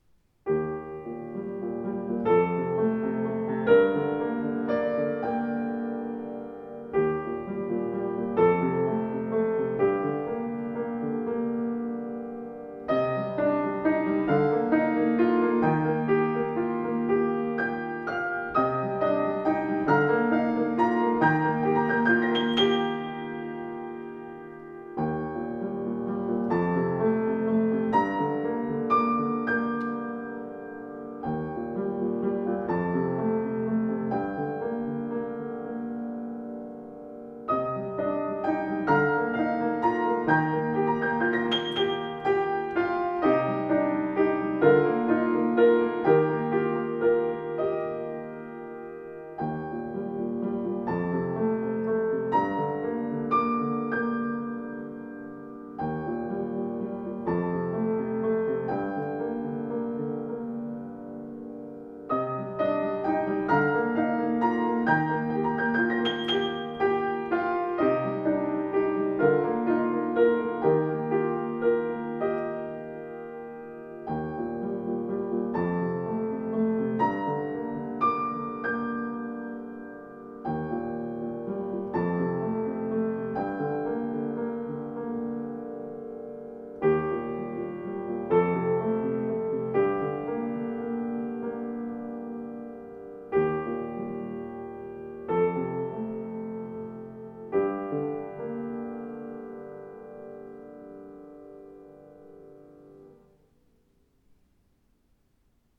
Klavier Schimmel 104 weiss gebraucht kaufen
Schöner, ausdruckstarker Klang, angenehme Spielart